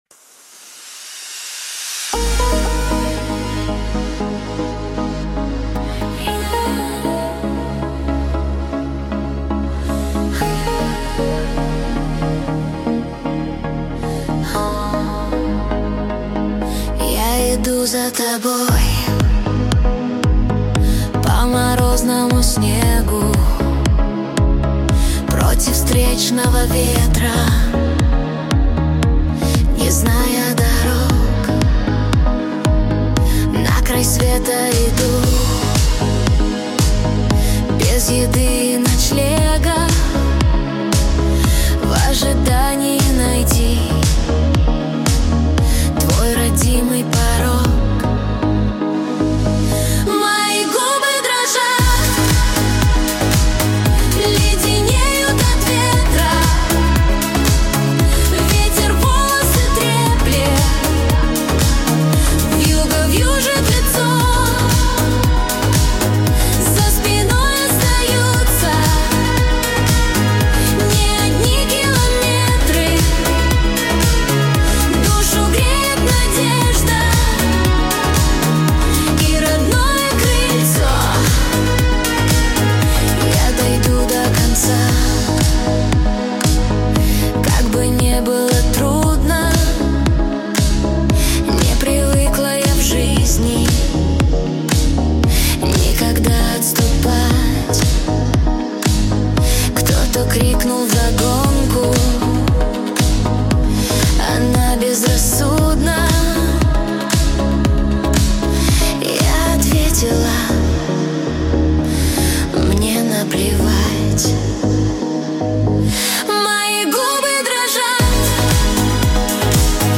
Качество: 128 kbps, stereo